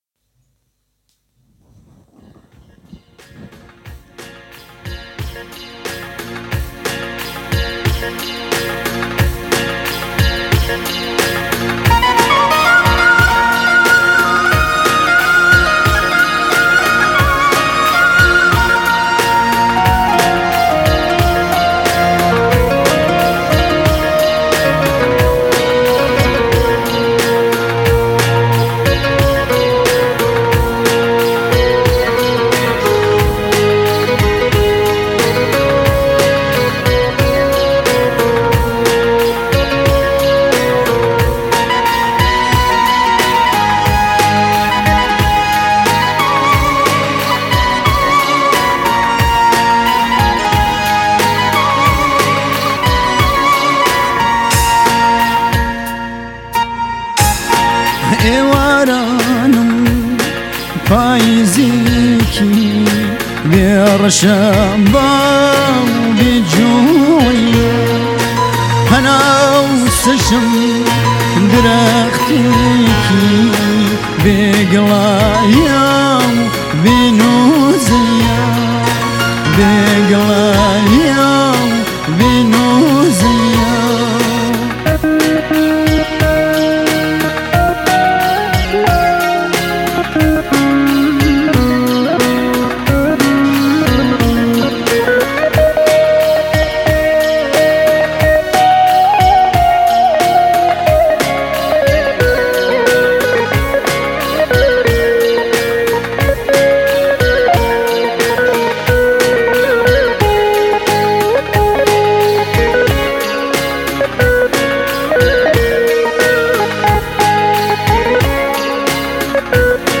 آهنگ کردی غمگین